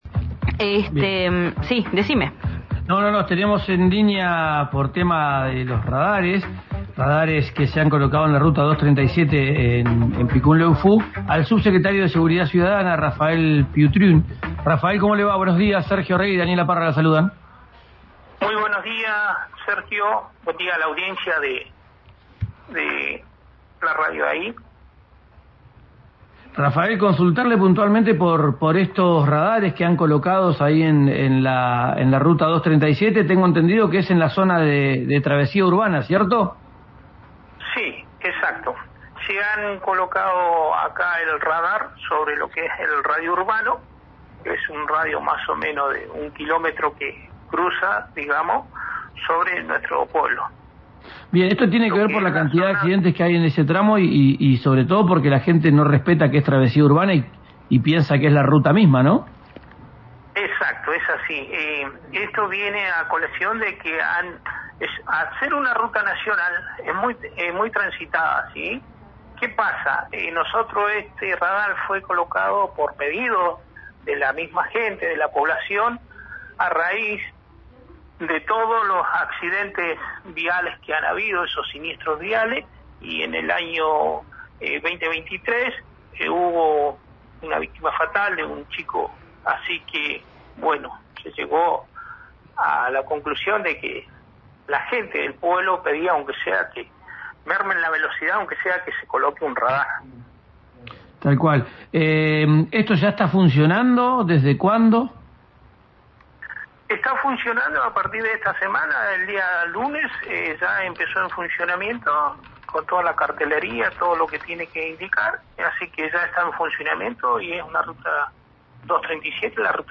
El subsecretario de seguridad ciudadana de Picún Leufú, Rafael Piutrin detalló en RIO NEGRO RADIO que «fue un pedido de la población».